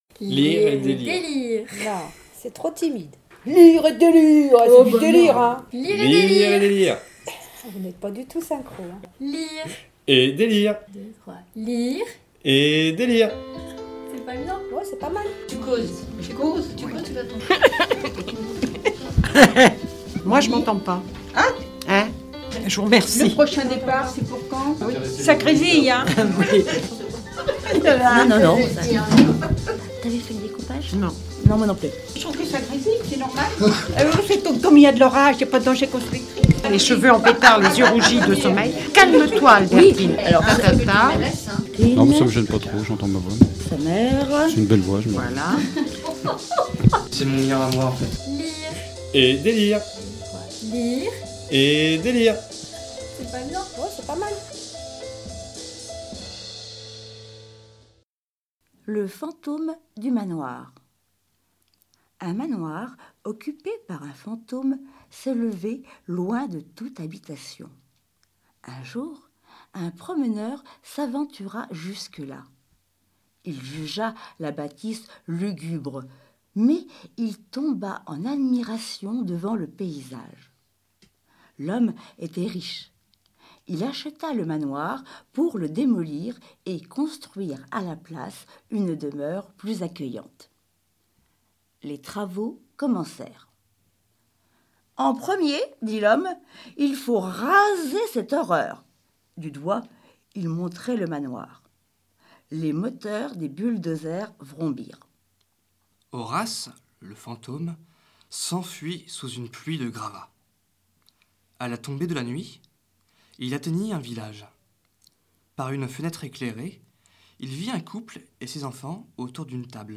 Les Haut Parleurs de l'association "Lire à Saint-Lô" prêtent leurs voix sur MDR dans une toute nouvelle émission "Lire et délire" !